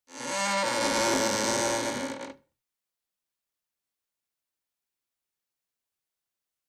Creak, Door
Heavy Close Wood Door Creaks